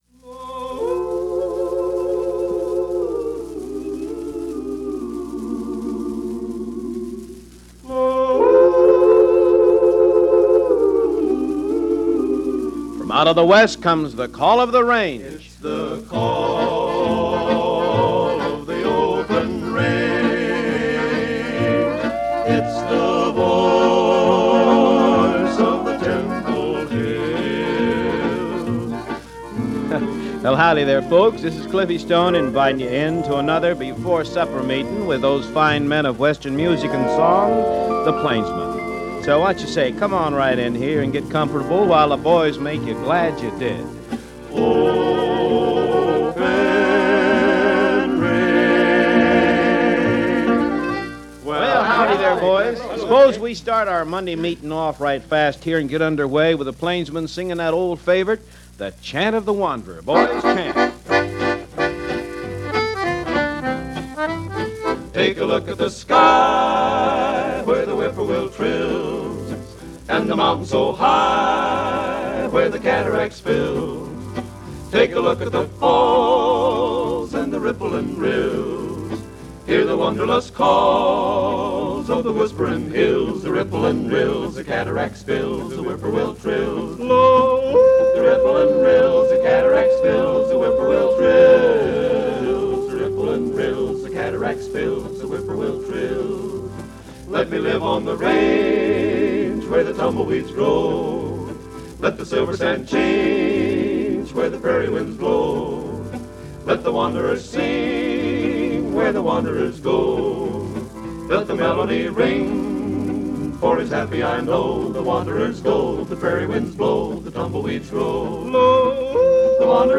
a singing group